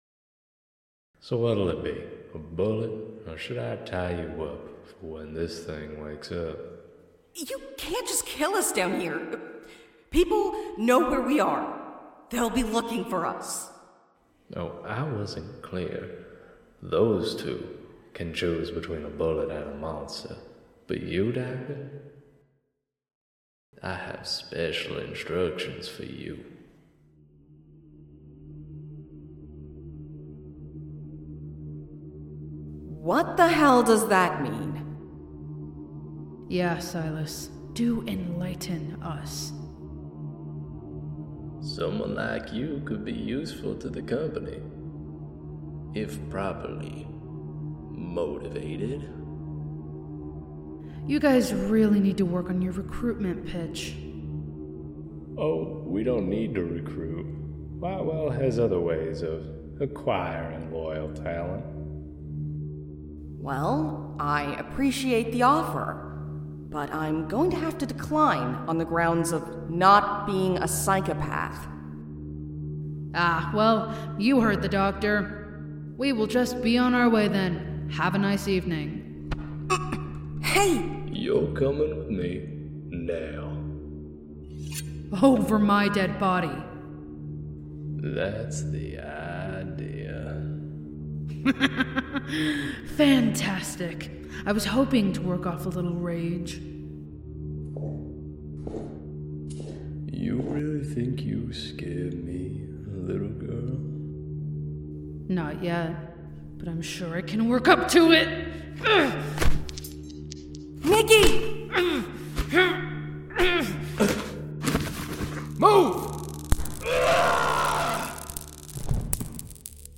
The Veins Below is a serialized supernatural horror audio drama that begins in a quiet Pennsylvania town, where a mysterious string of disappearances has been occurring for the past two years. The investigation draws an unlikely trio together in the search for answers, where they begin to unravel the threads of a conspiracy that stretches far beyond anything they could have ever imagined.